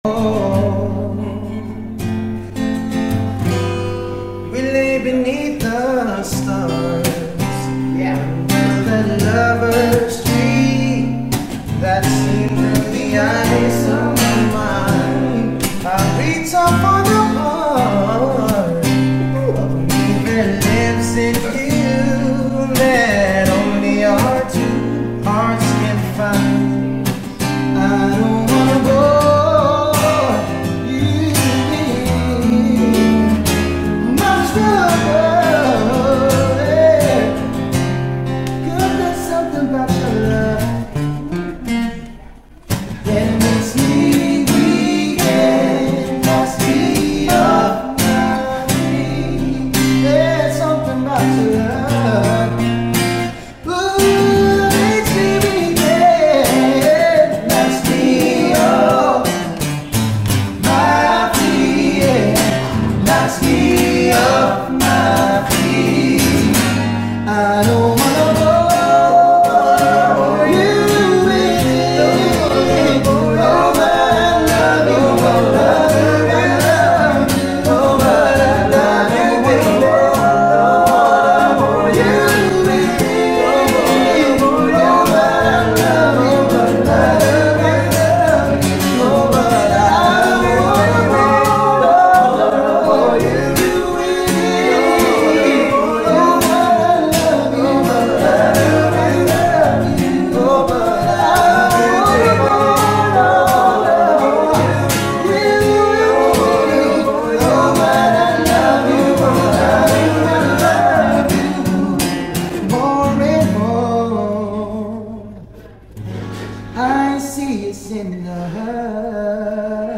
RnB Boyband ng Pinas